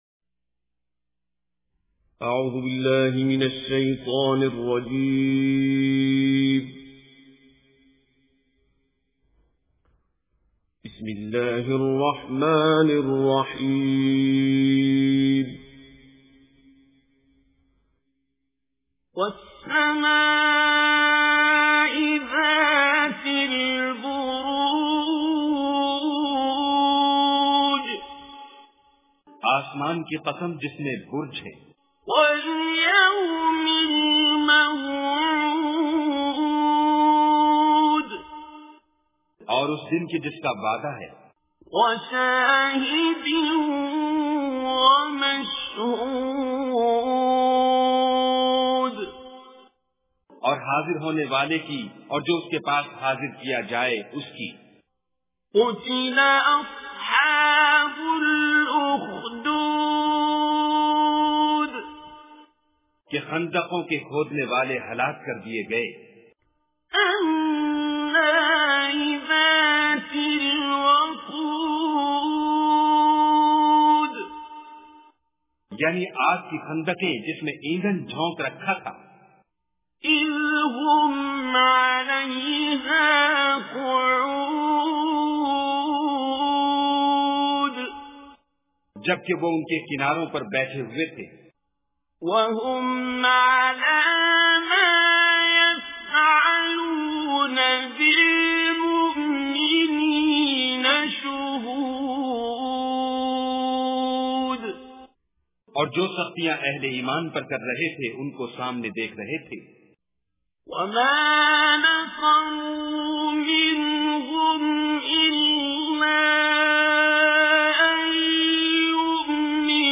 Surah Al-Burooj Recitation with Urdu Translation
Surah Al-Burooj is 85th chapter or surah of Holy Quran. Listen online and download mp3 tilawat / recitation of Surah Al-Burooj in the beautiful voice of Qari Abdul Basit As Samad.